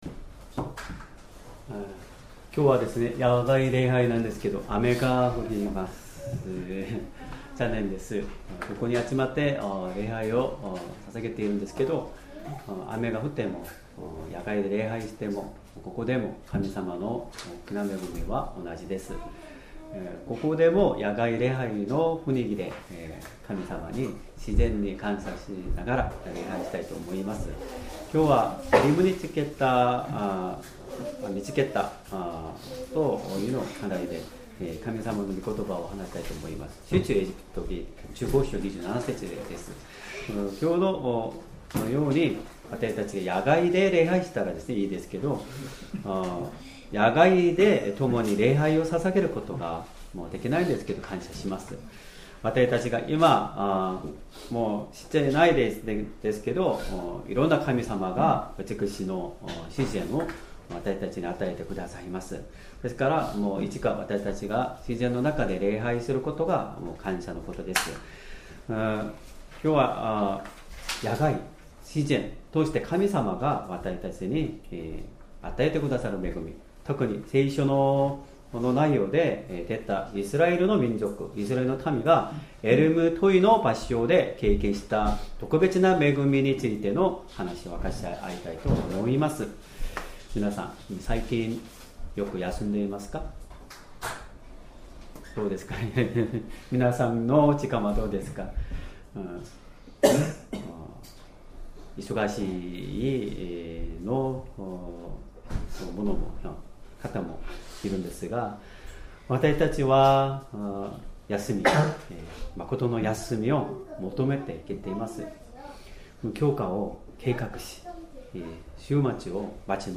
Sermon
2025年10月26日 主日礼拝 説教 「ローマへの道」 聖書 出エジプト記15章27節 司式者 15:27 こうして彼らはエリムに着いた。